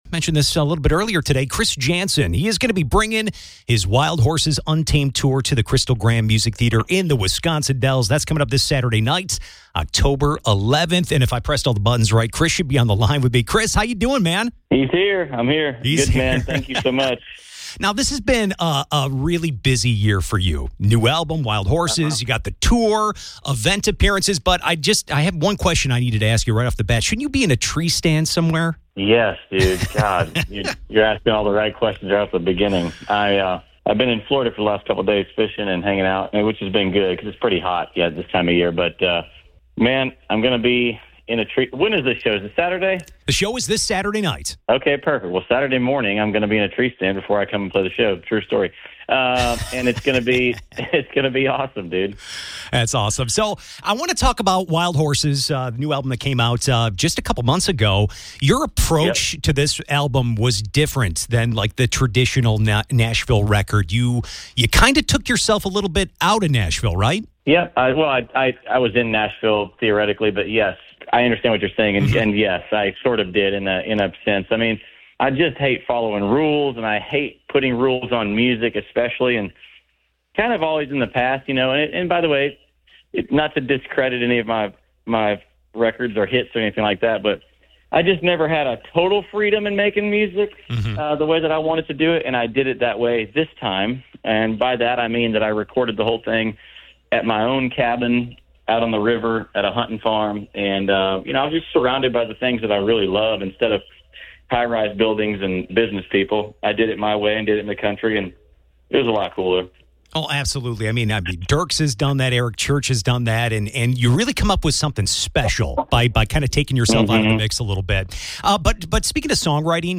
Chris Janson Interview